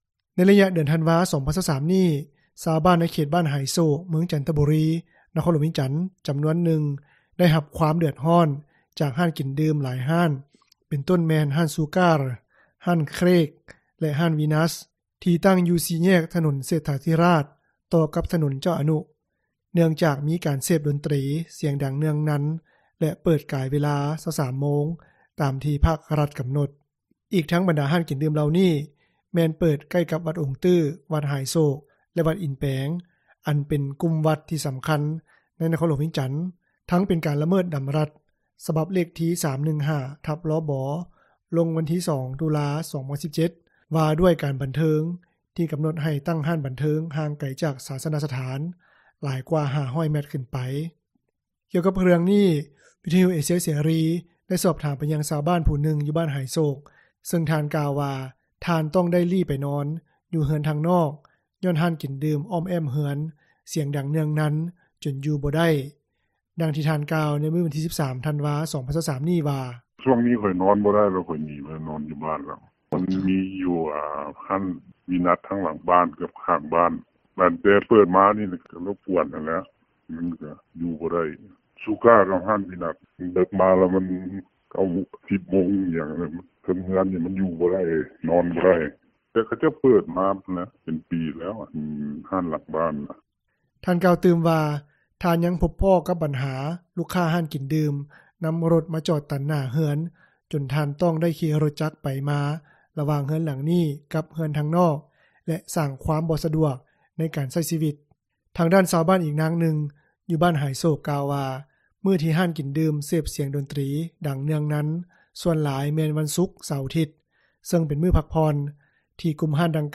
ກ່ຽວກັບເຣື່ອງນີ້, ວິທຍຸເອເຊັຽເສຣີ ໄດ້ສອບຖາມ ໄປຍັງຊາວບ້ານ ຜູ້ນຶ່ງ ຢູ່ບ້ານຫາຍໂສກ, ເຊິ່ງທ່ານກ່າວວ່າ ທ່ານຕ້ອງໄດ້ລີ້ໄປນອນ ຢູ່ເຮືອນທາງນອກ ຍ້ອນຮ້ານກິນດື່ມ ອ້ອມແອ້ມເຮືອນ ສຽງດັງເນືອງນັນ ຈົນຢູ່ບໍ່ໄດ້.
ດັ່ງທີ່ພຣະອາຈານ ກ່າວວ່າ: